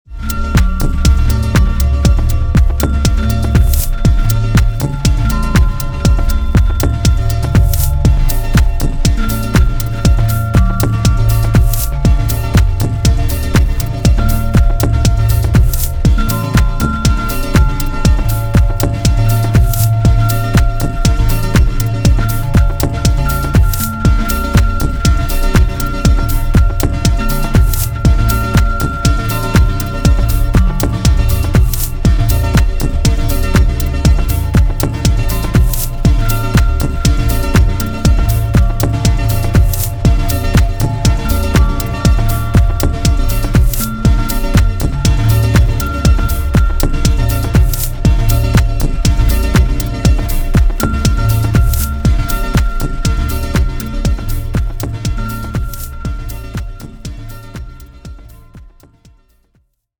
諸要素を絞り、音響のサイケデリックな効果に注力したミニマル・ハウスのモダンな最新形。